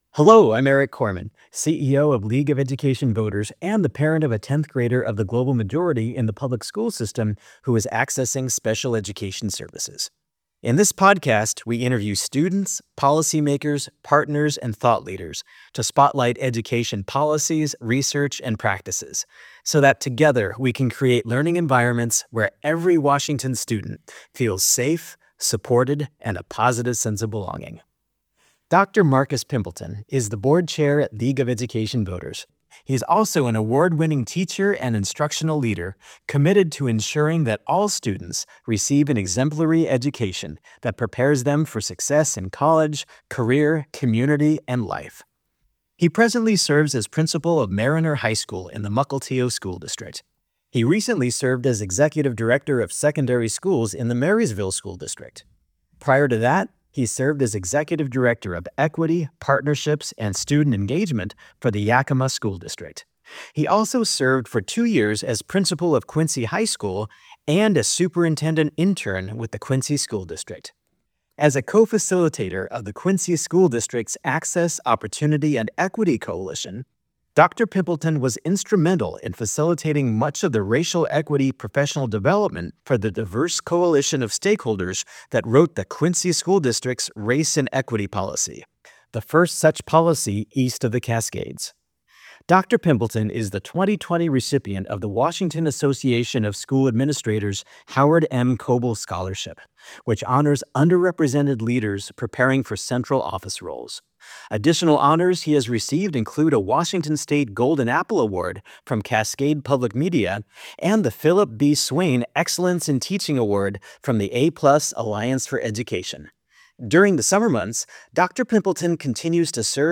In our Putting Students First podcast, we interview students, policymakers, partners, and thought leaders to spotlight education policies, research, and practices so that together we can create learning environments where every Washington student feels safe, supported, and a positive sense of belonging.